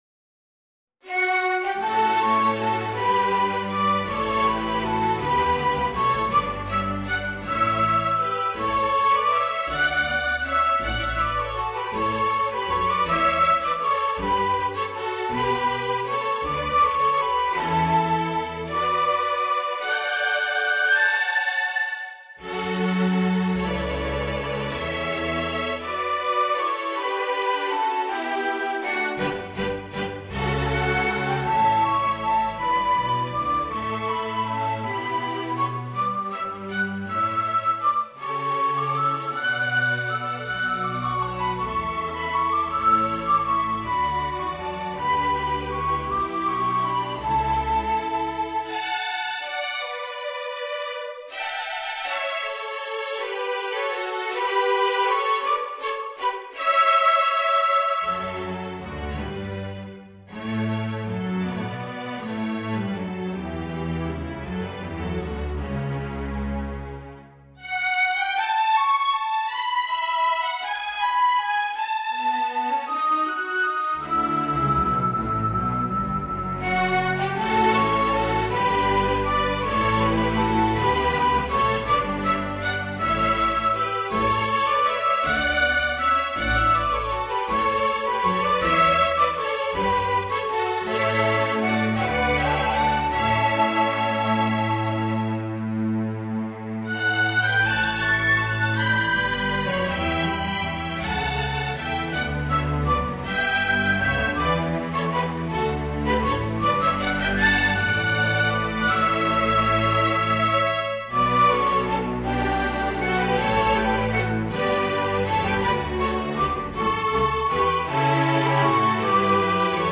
Mock-ups generated by NotePerformer can be heard below (to listen, click on the white arrowhead on the left of the bar).
A tonal and melodic work of three movements with a playing time of 9 minutes:
fantasia-for-flute-and-strings-i.mp3